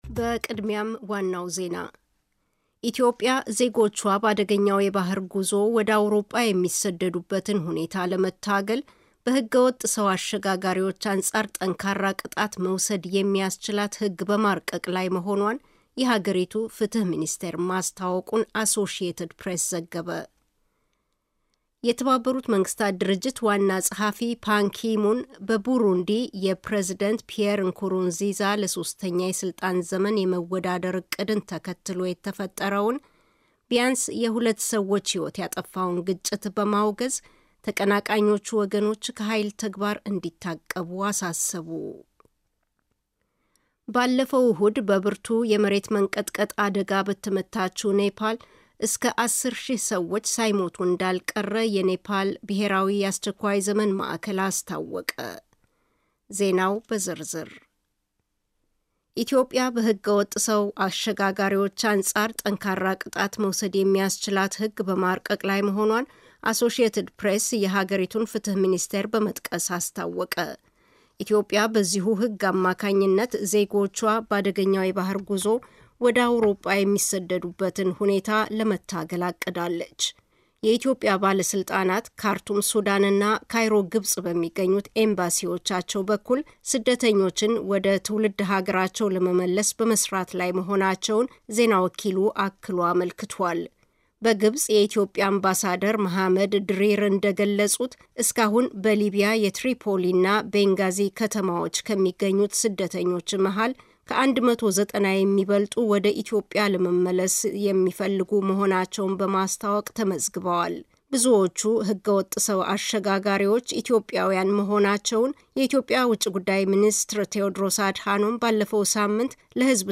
DW Amharic ዜና 28.04.2015